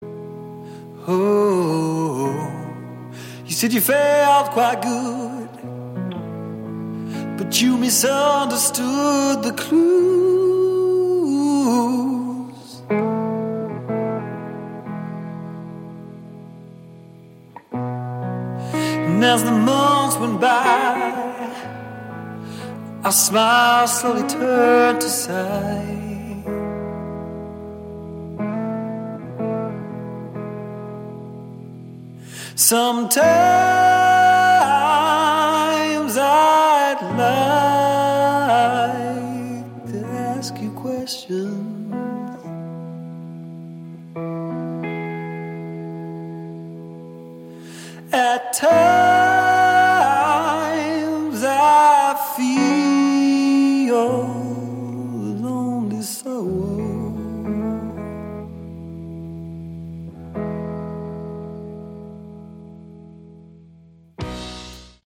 Category: Bluesy Hard Rock
vocals, harmonica
guitar, backing vocals
drums
bass